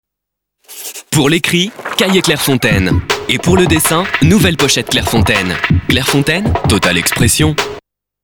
VOIX OFF JEUNE
Un timbre plus léger, une énergie rajeunie, un phrasé plus direct — tout est ajusté avec précision selon les besoins du projet.
3. CLAIREFONTAINE ado